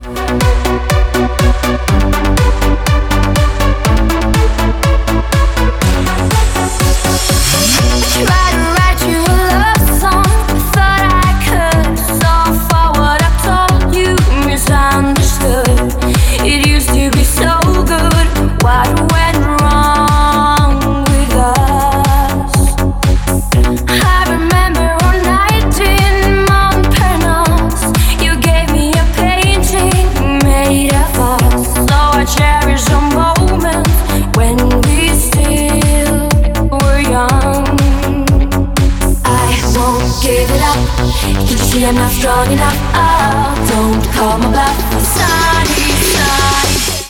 • Качество: 128, Stereo
ритмичные
громкие
женский вокал
Club House
Eurodance